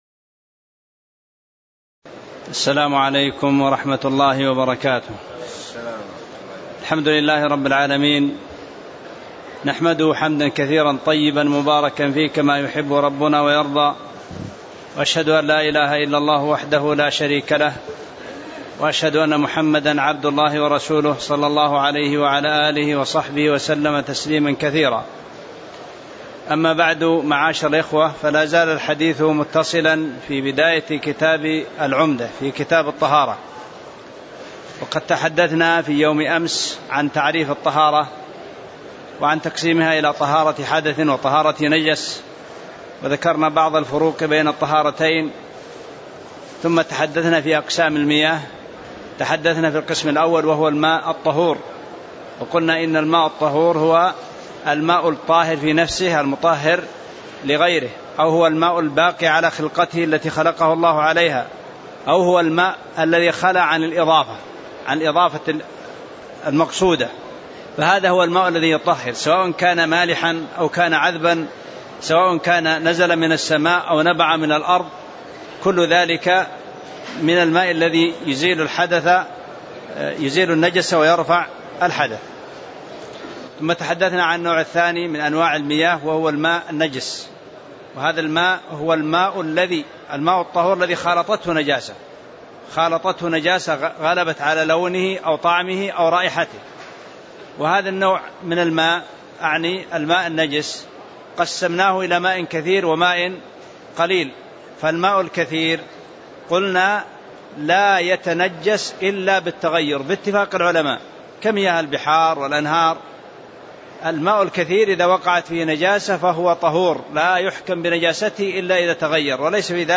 تاريخ النشر ٢٦ ذو الحجة ١٤٣٧ هـ المكان: المسجد النبوي الشيخ